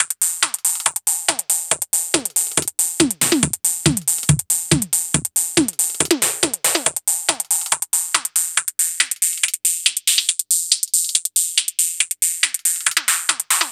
NRG 4 On The Floor 038.wav